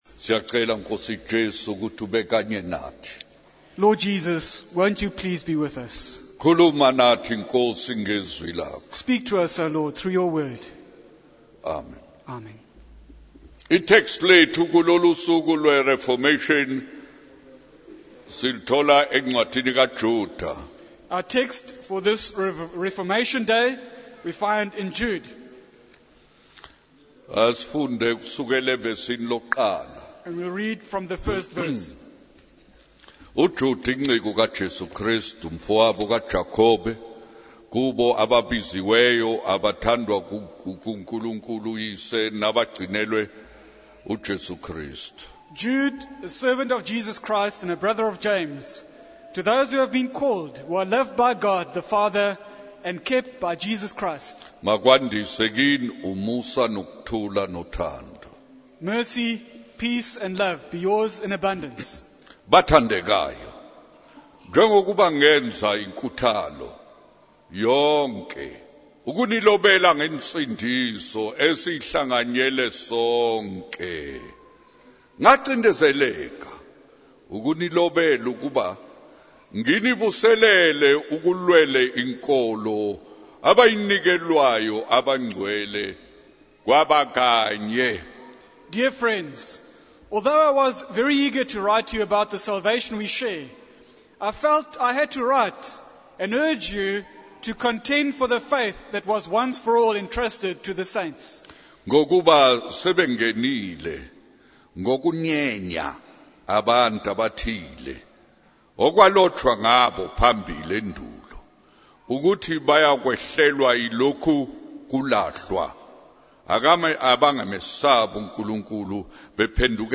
In this sermon, the speaker recounts a conversation with their grandfather who complained about his physical ailments. The speaker emphasizes the importance of addressing sin before focusing on physical pain.